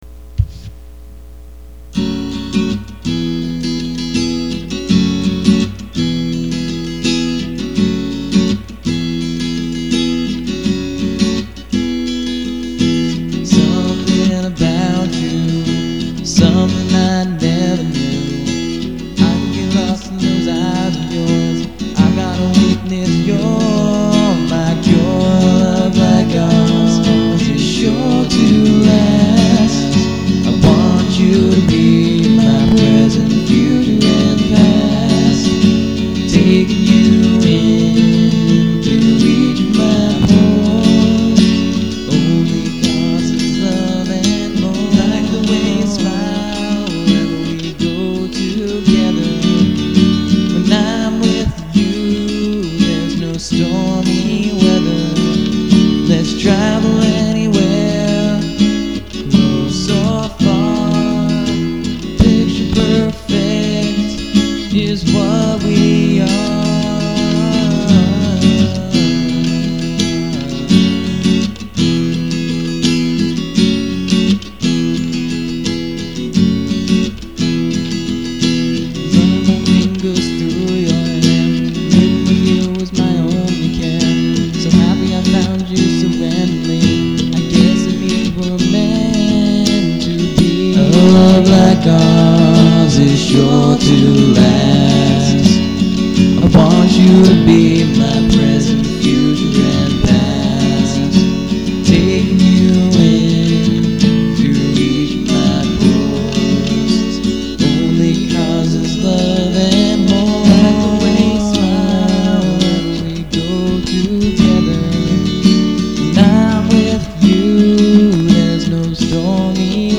Picture_Perfect_Acoustic.mp3